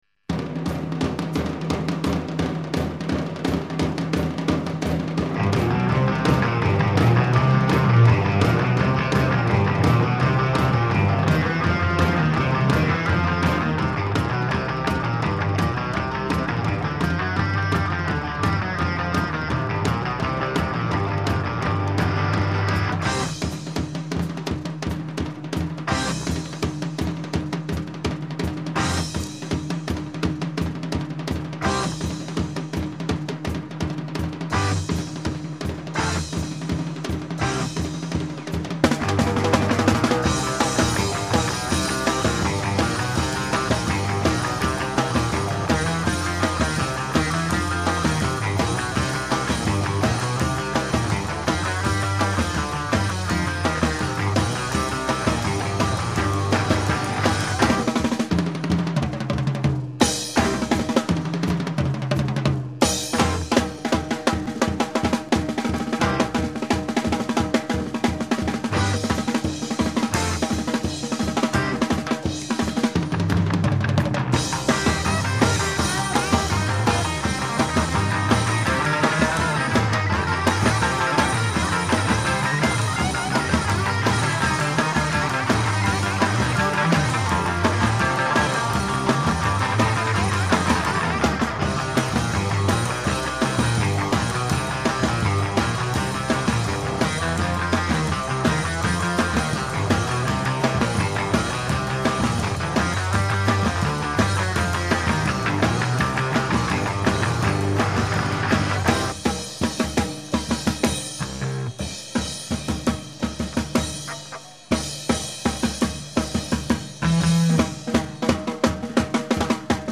Reproducing the Summer of 1965 Japan concerts.